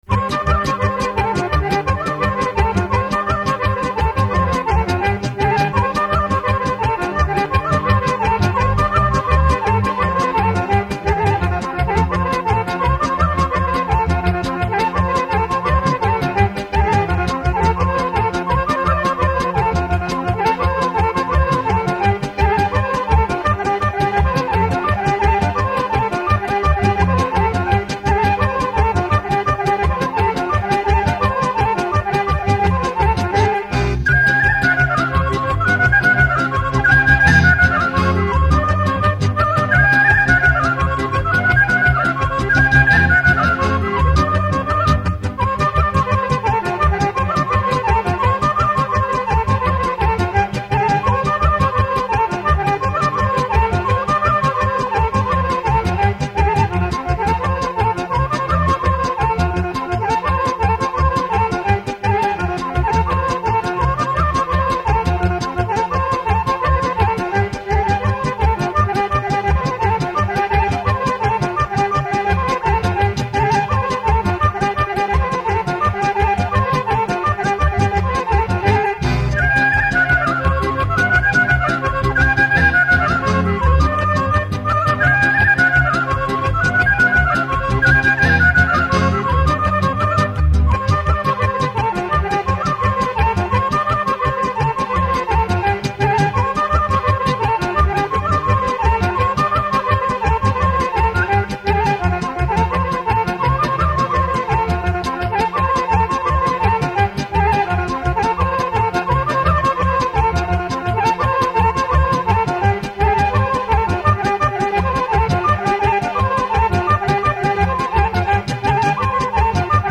Iz fonoteke Muzeja u Majdanpeku:
50 bisera vlaške muzike!